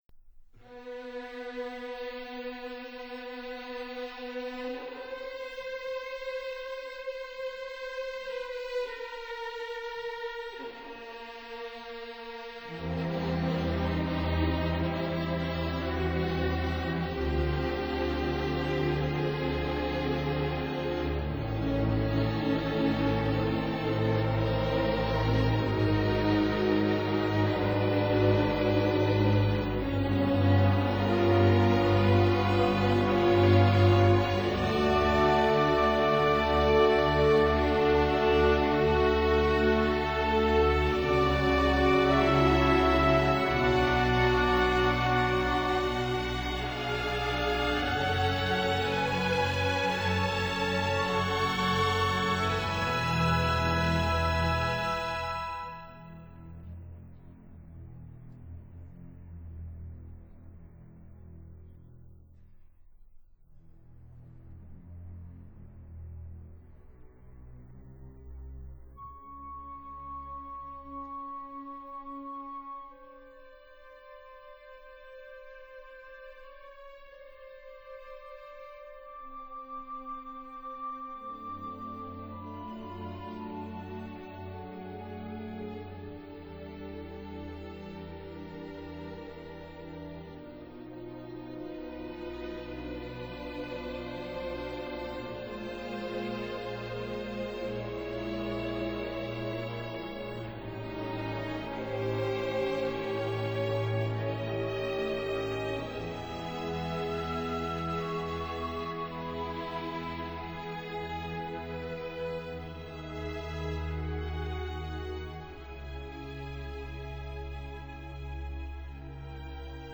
Recording venue: Orchestra Hall, Minneapolis, USA